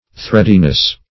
Threadiness \Thread"i*ness\